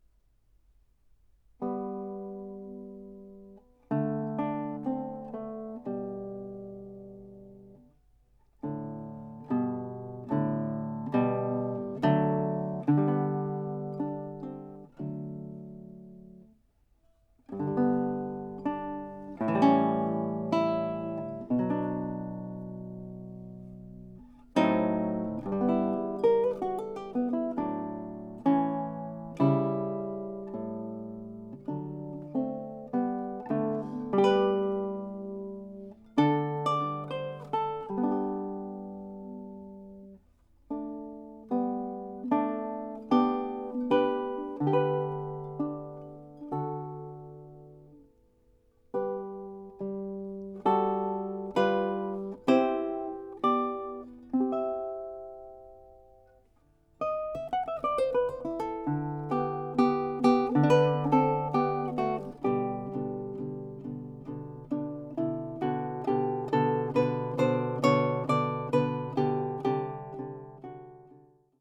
Sono qui presentate tre sonate per chitarra sola
chitarra